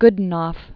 (gdn-ôf, gŏd-, gə-d-nôf), Boris Fyodorovich 1551?-1605.